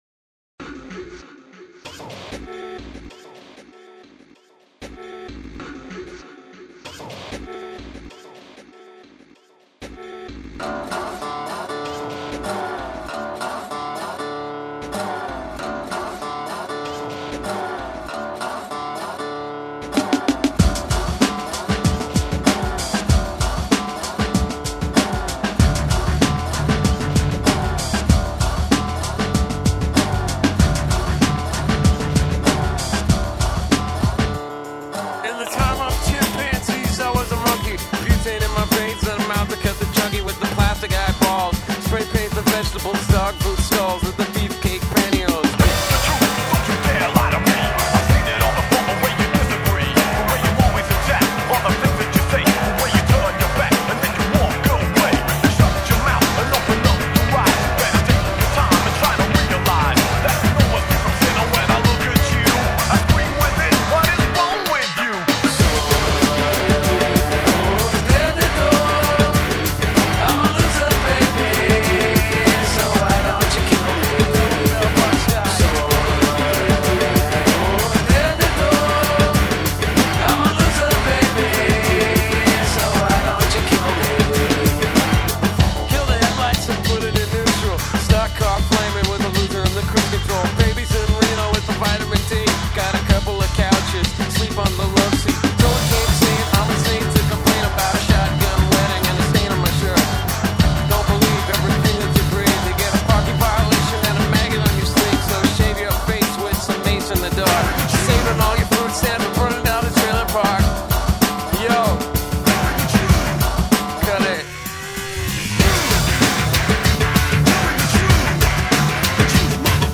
B O O T L E G S
Pour les aficionados de grosses guitares et de groove ...